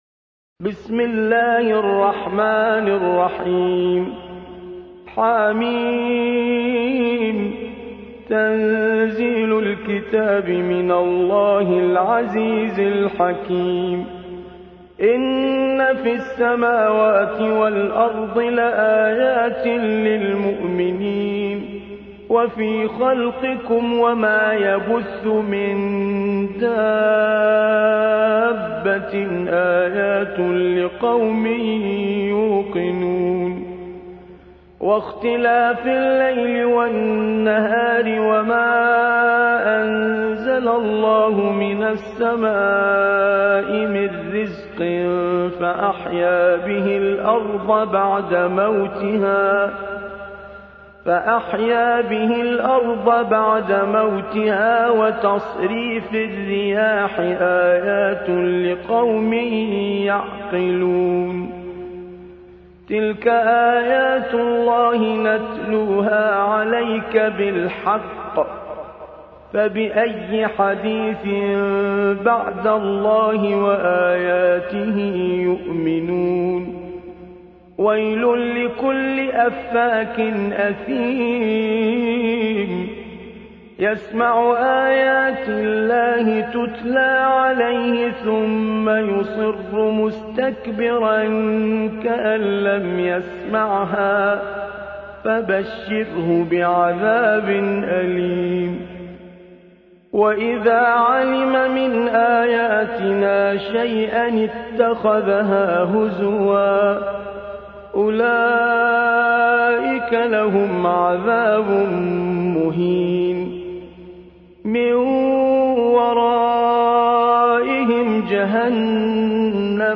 موقع يا حسين : القرآن الكريم 45.